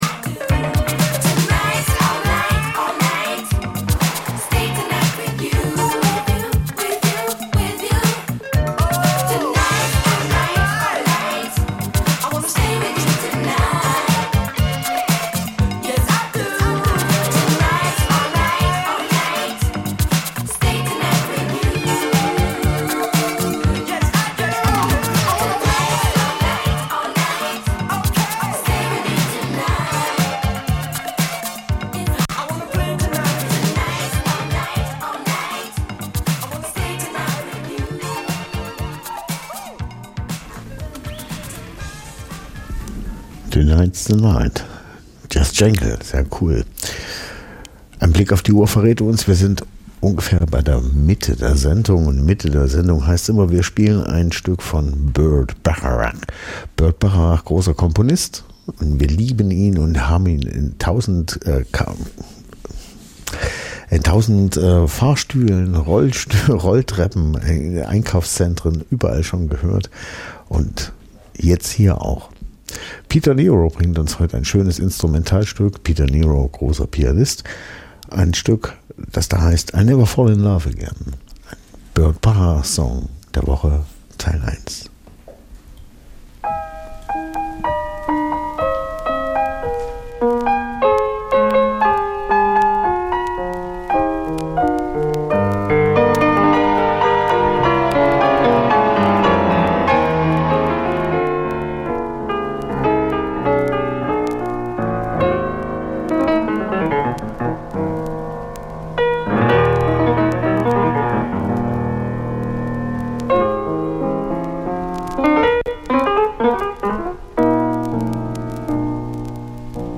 Easy Listening Dein Browser kann kein HTML5-Audio.